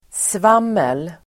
Ladda ner uttalet
Uttal: [sv'am:el]